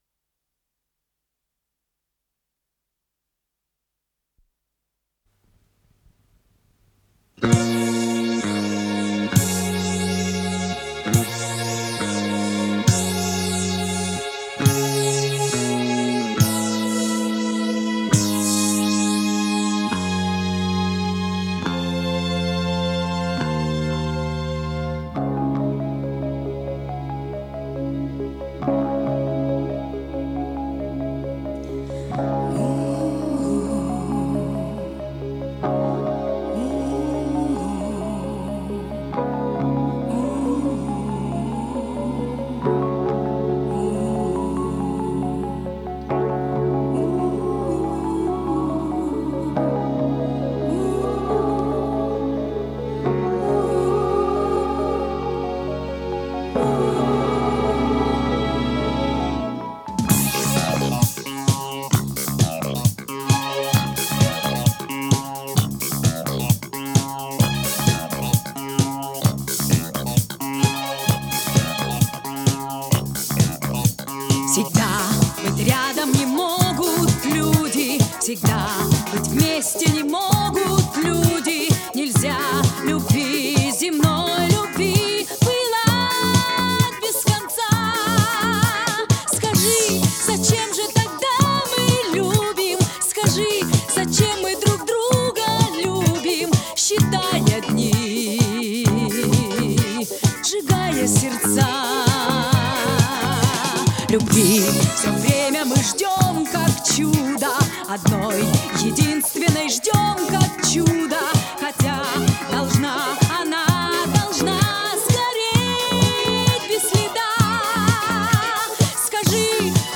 с профессиональной магнитной ленты
пение
ВариантДубль моно